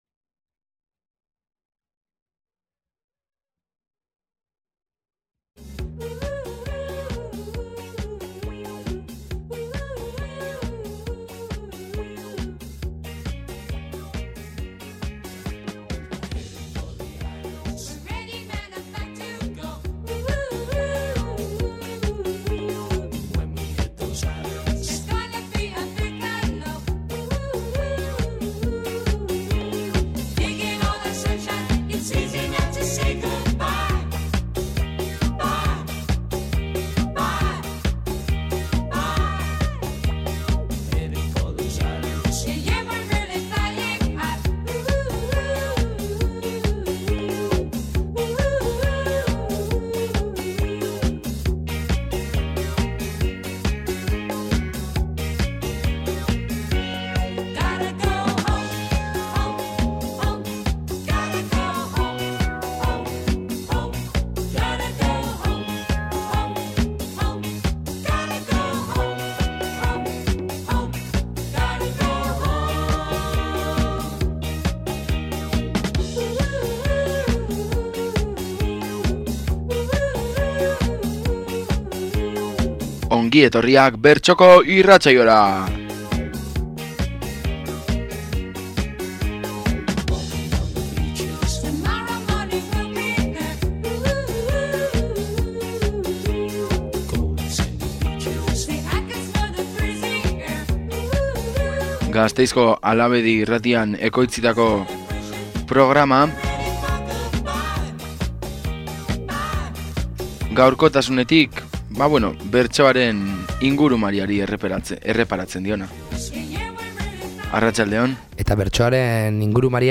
Gaurkoan bertxokon Arabako Kuadrilla Artekoko Kanpezuko saioko bertso-sortak, Gareseko bertso jazza eta askoz gehiago.